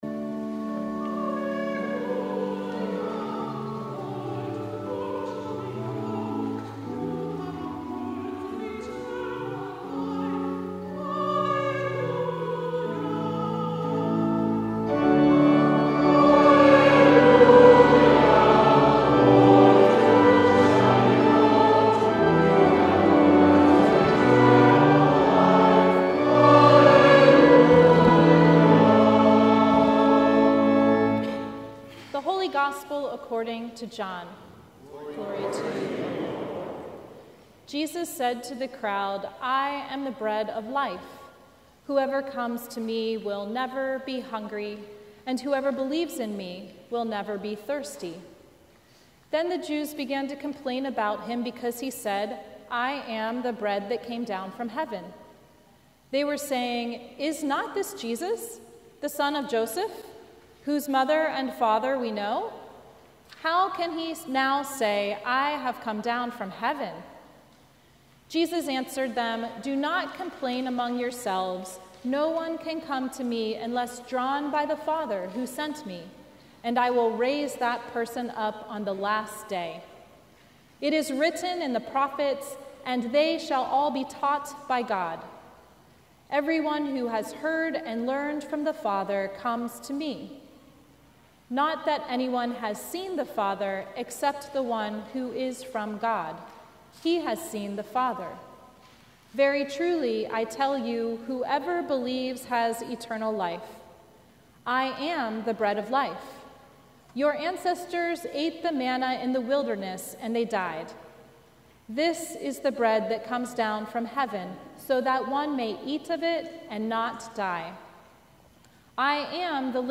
Sermon from the Twelfth Sunday After Pentecost